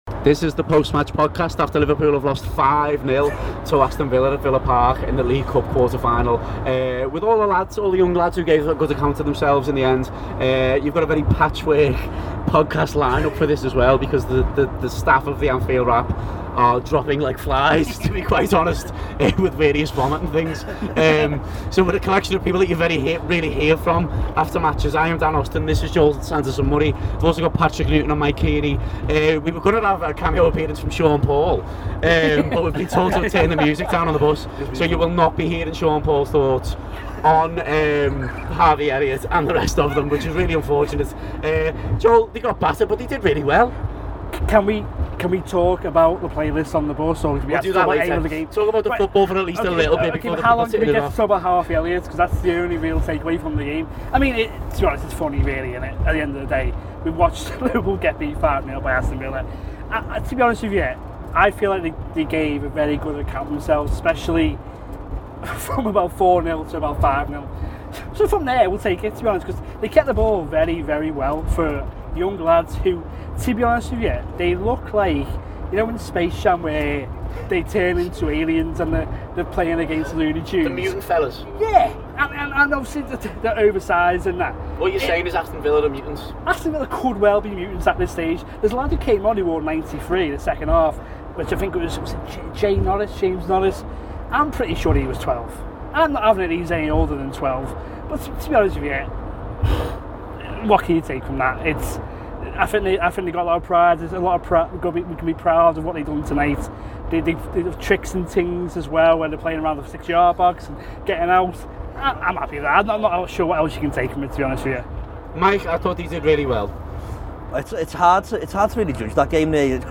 to give their immediate post-match reaction